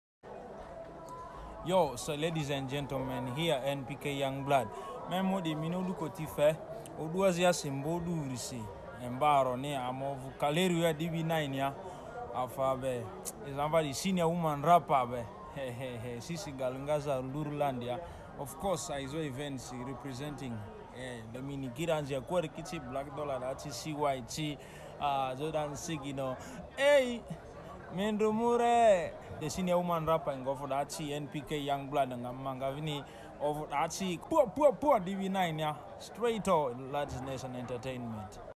will be vibing with hard bars